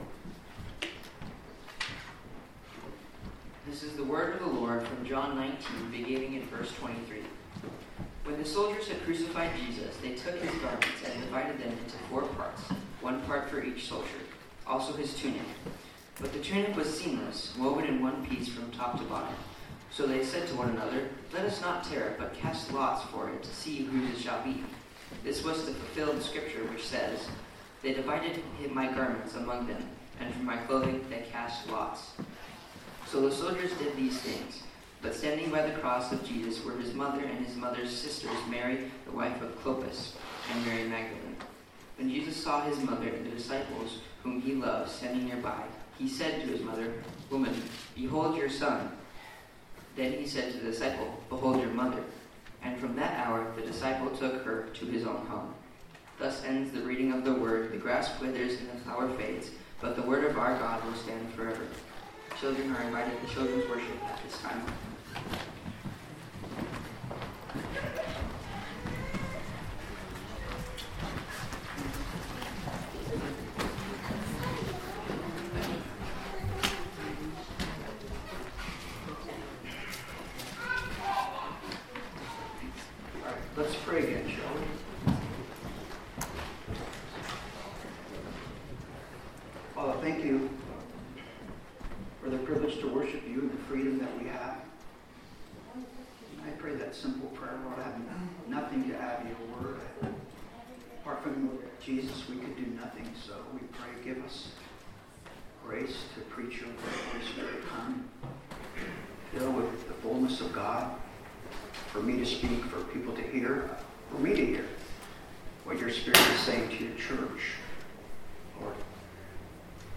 Service Type: Sunday Morning Topics: at the cross , Jesus' care for his mother , Jesus' confidence in John's love , Jesus' creation of his church , the world takes notice of our love for one another